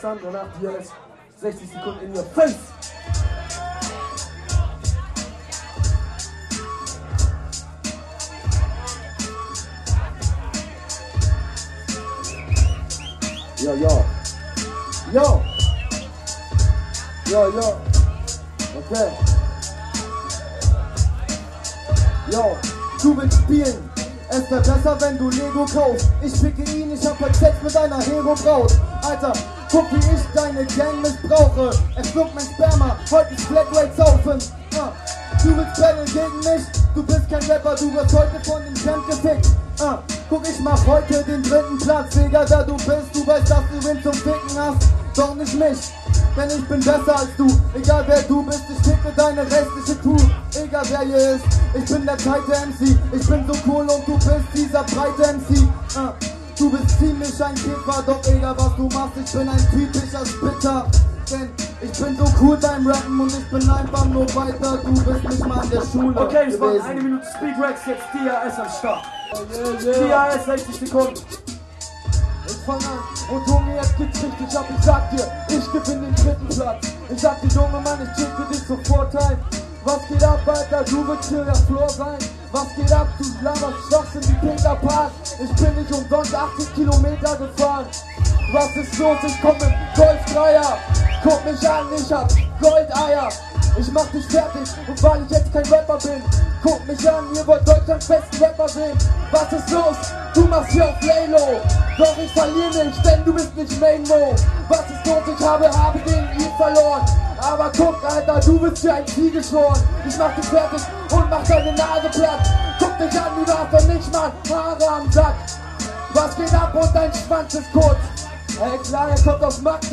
folgende MCs traten die Vorrunde an:
Das kleine Finale: